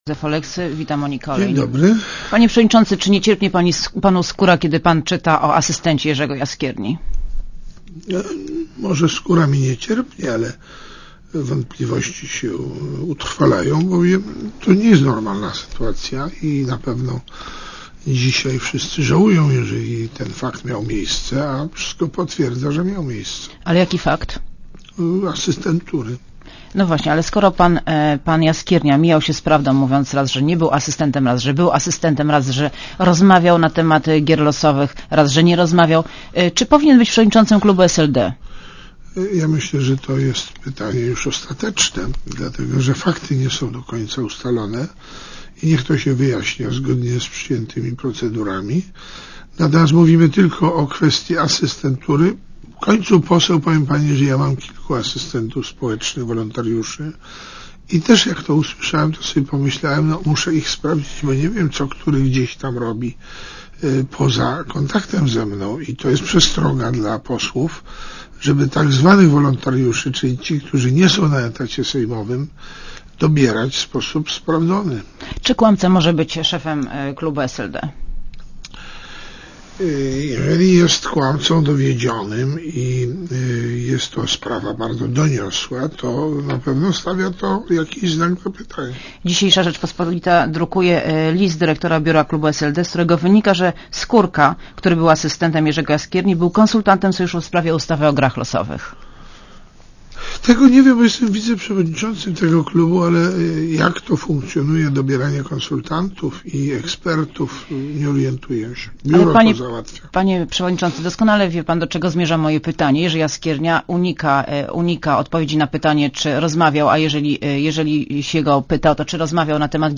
© (RadioZet) Posłuchaj wywiadu A gościem Radia Zet jest wiceprzewodniczący SLD Józef Oleksy.